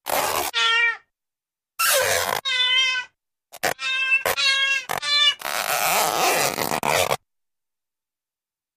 Various Ripping Stretches with Light Cat Yowl After Stretches, Becomes Shorter & Faster Towards Tail ( I.e. Stretching Cat ).